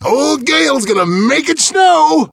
gale_ulti_vo_03.ogg